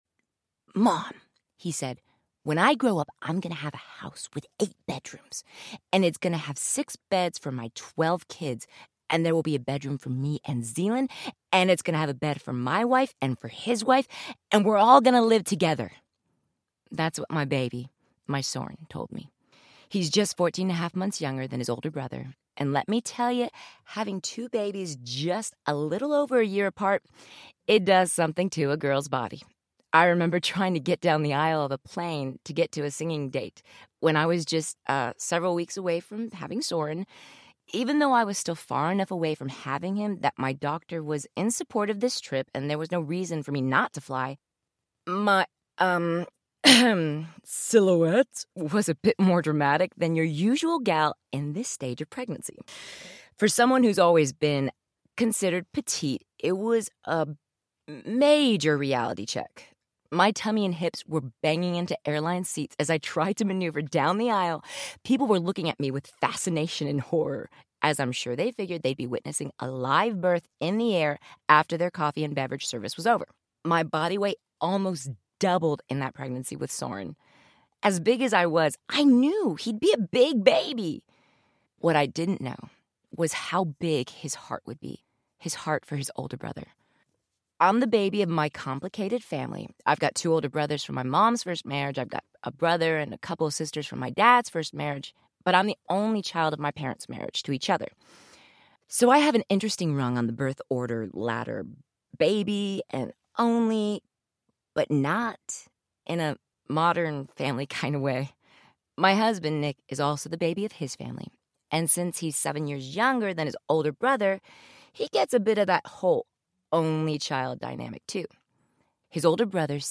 When God Rescripts Your Life Audiobook
5.5 Hrs. – Unabridged